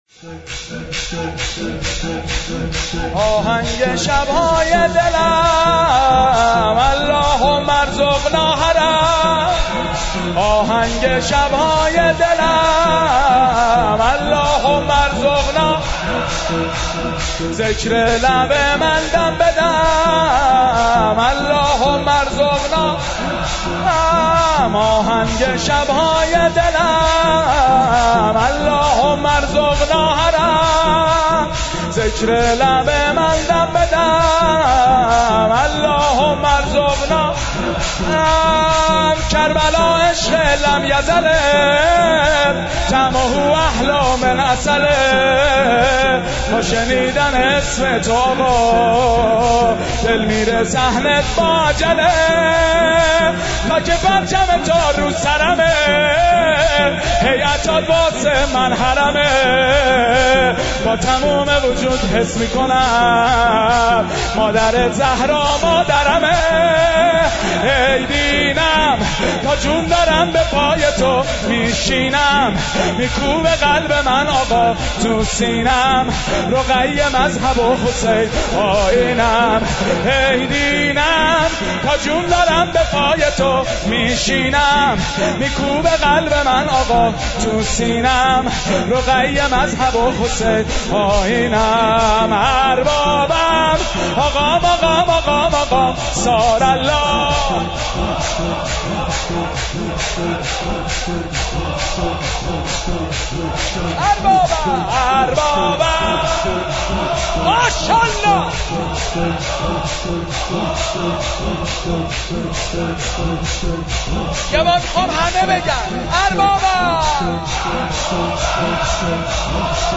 مراسم شب نوزدهم ماه رمضان
مداحی
درمسجد کربلا برگزار گردید.